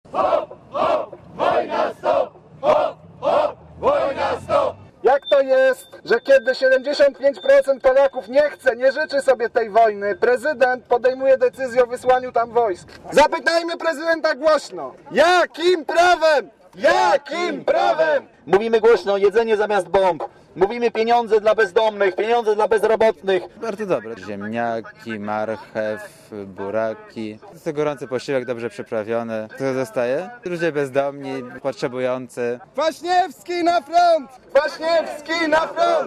(PAP) Ponad 100 osób zebrało się przed Pałacem Prezydenckim w Warszawie, by protestować przeciwko udziałowi Polski w wojnie w Iraku.
Inny uczestnik protestu pytał przez megafon, jakim prawem polskie władze wysyłają wojsko na wojnę w Iraku, podczas gdy konstytucja mówi, że o udziale w wojnie decyduje Sejm i to w przypadku bezpośredniego zagrożenia granic kraju, albo państwa sojuszniczego.